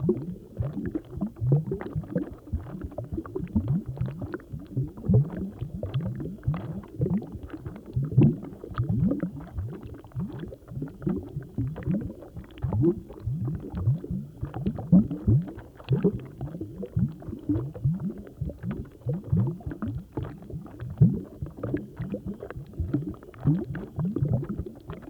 DEEP BUBB0CR.wav